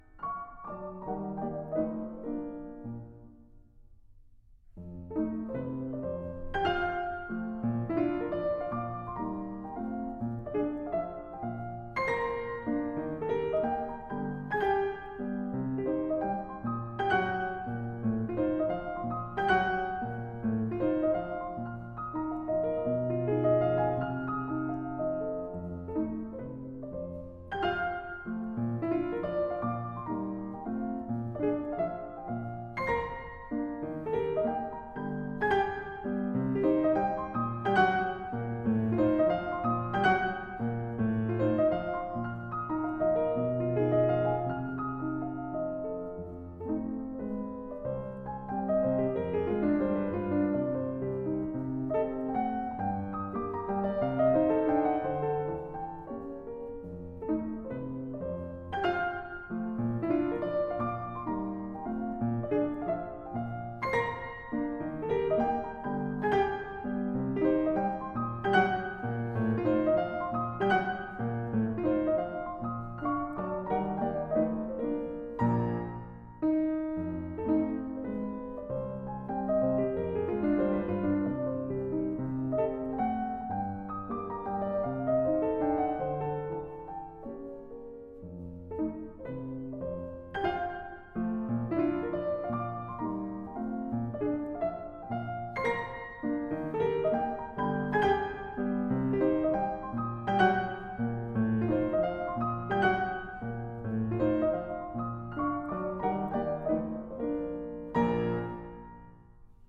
solo recital